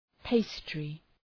{‘peıstrı}